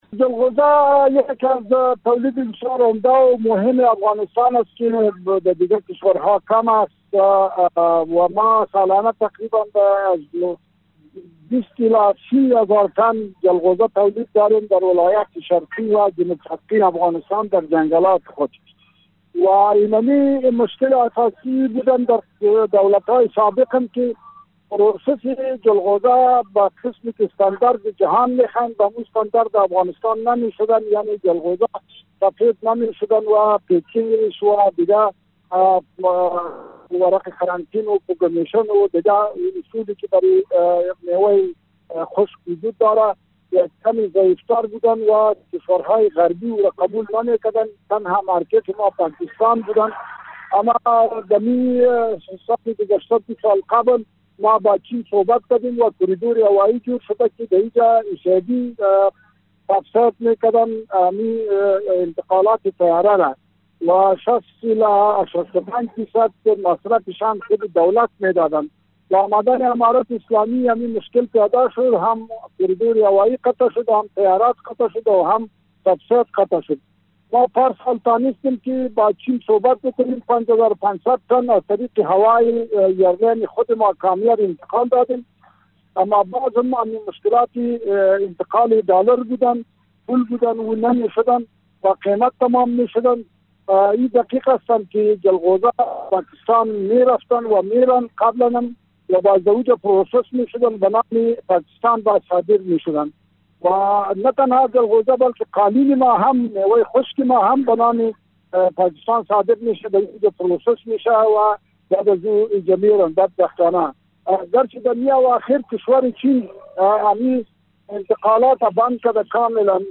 در گفت و گو با بخش خبر رادیو دری